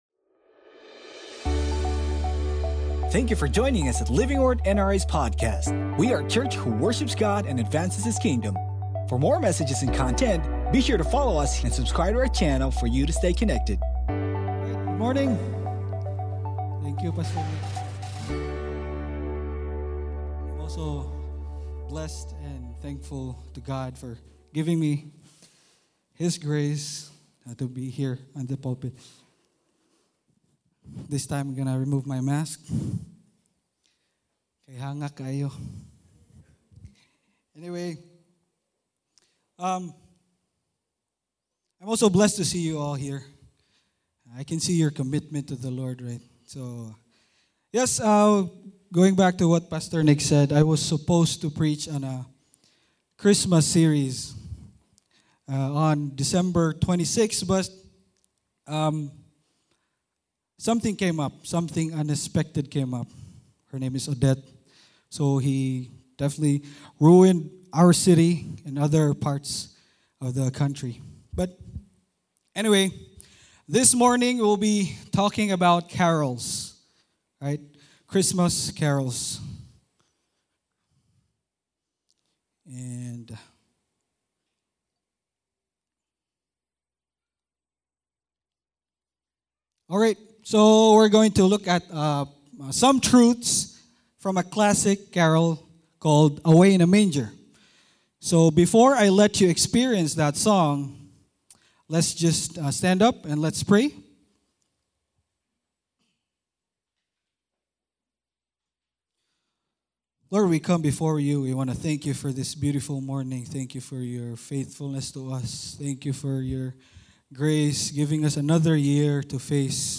Sermon Title: AWAY IN A MANGER Scripture Text: LUKE 2:10-11 Sermon Series: CHRISTMAS CAROLS Sermon Notes: Luke 2:10-11 NIV 10…Do not be afraid.